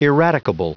Prononciation du mot eradicable en anglais (fichier audio)
Prononciation du mot : eradicable